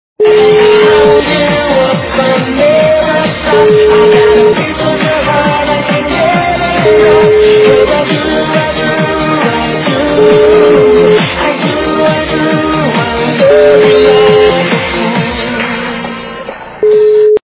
- западная эстрада
качество понижено и присутствуют гудки.